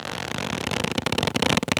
foley_leather_stretch_couch_chair_05.wav